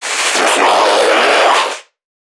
NPC_Creatures_Vocalisations_Infected [21].wav